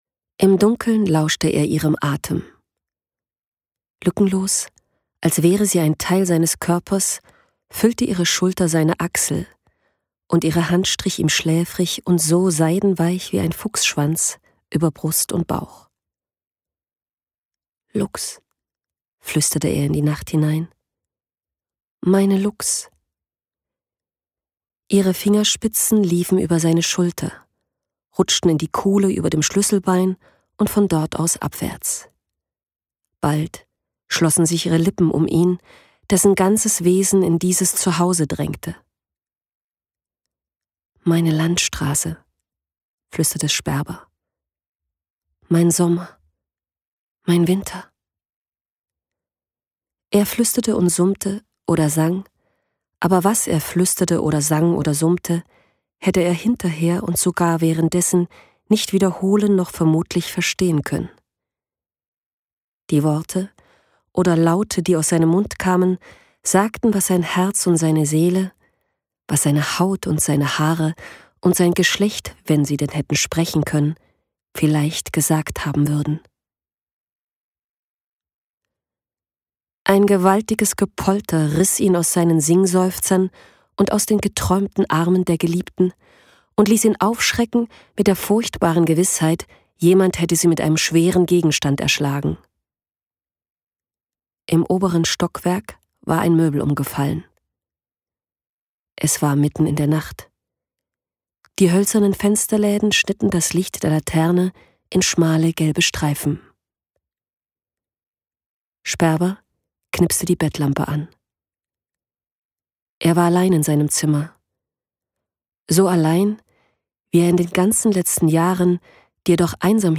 Hörspiel Amelia